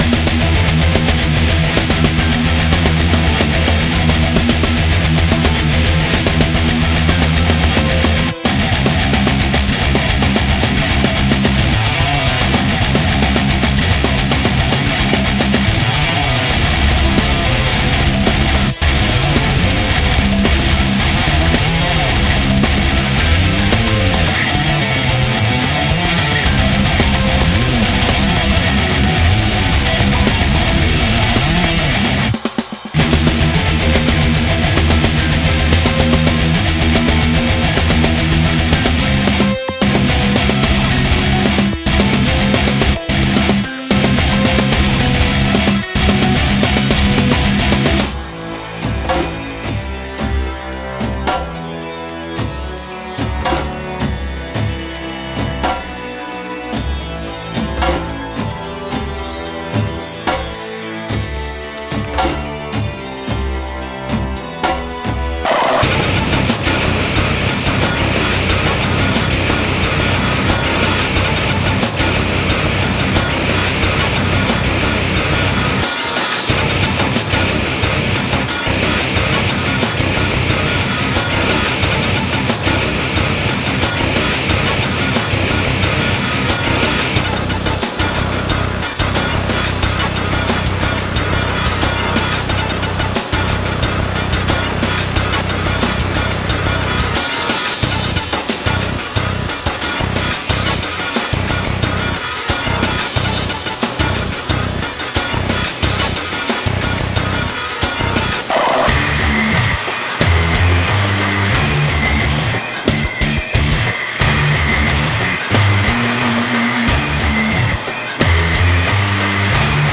Genre: Progressive, Experimental Metal
Instruments: guitars, programming